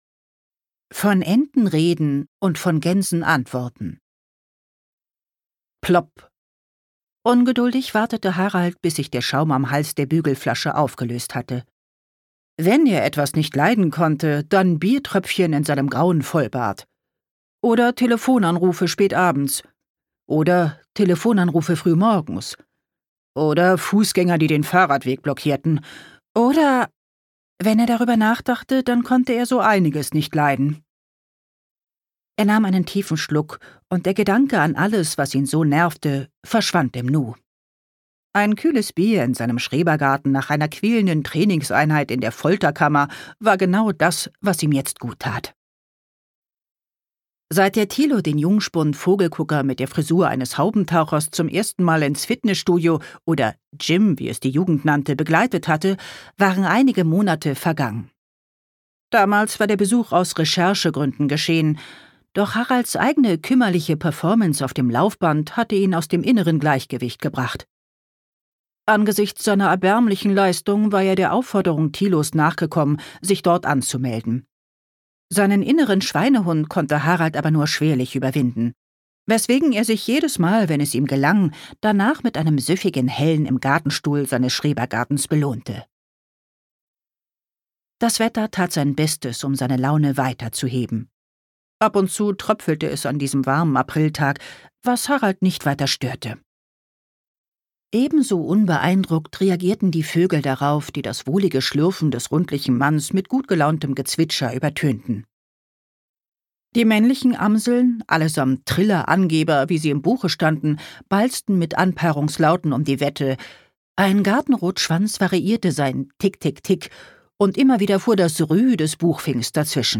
Cosy Crime – Vogelfreunde aufgepasst!
Gekürzt Autorisierte, d.h. von Autor:innen und / oder Verlagen freigegebene, bearbeitete Fassung.